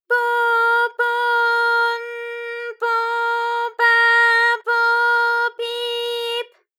ALYS-DB-001-JPN - First Japanese UTAU vocal library of ALYS.
po_po_n_po_pa_po_pi_p.wav